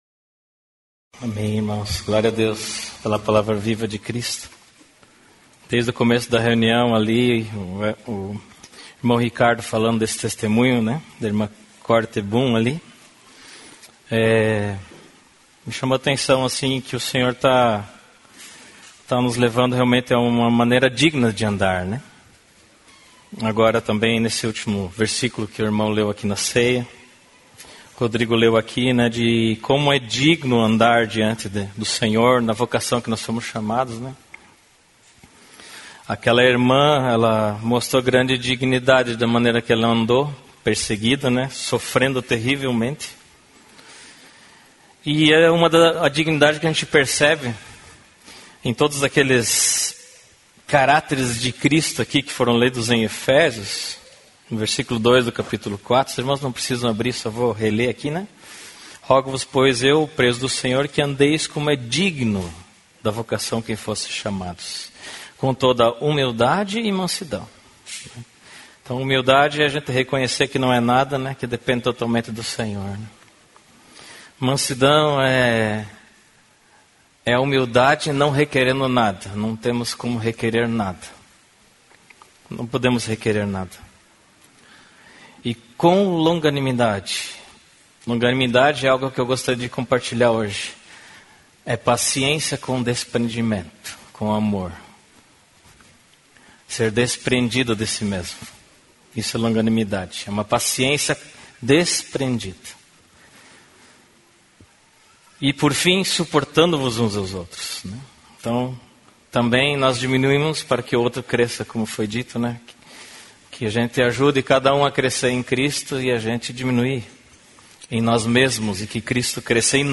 Mensagem compartilhada
na reunião da igreja em Curitiba